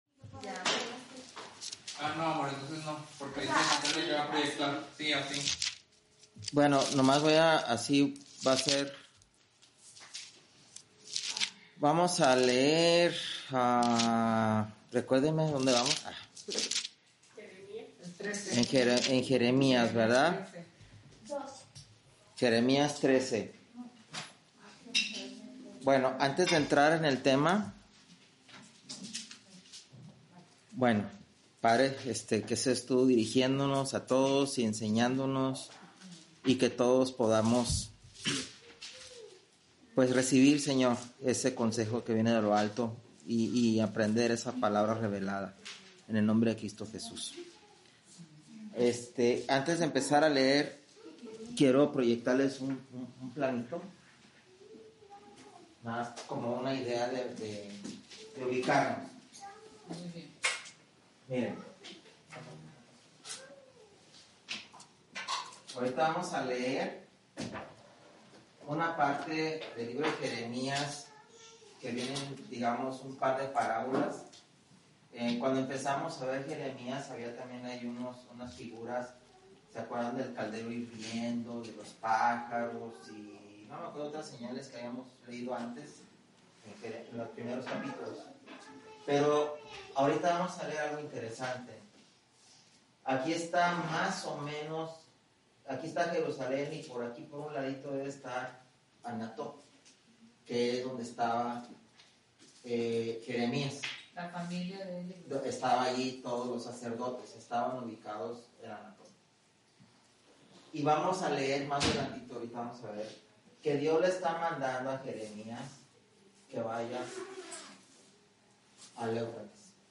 Estudio de lunes sobre el libro de Jeremías.